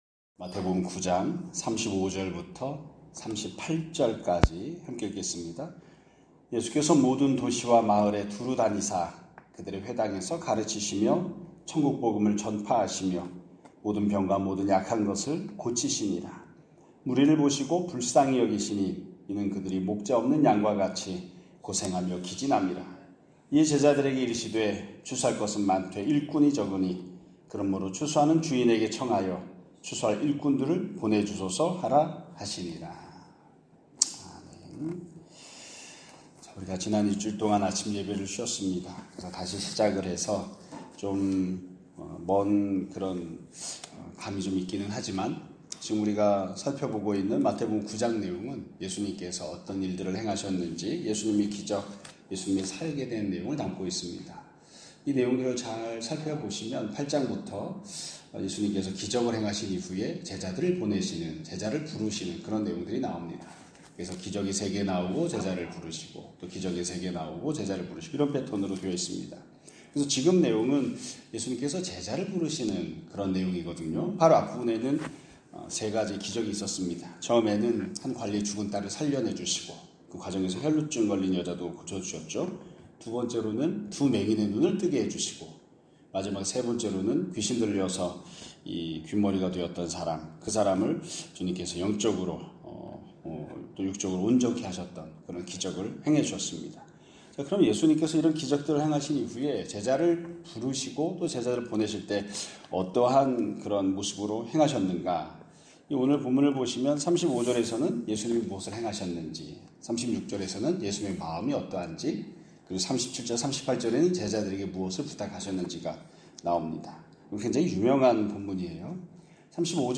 2025년 8월 4일 (월요일) <아침예배> 설교입니다.